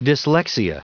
Prononciation du mot dyslexia en anglais (fichier audio)
Prononciation du mot : dyslexia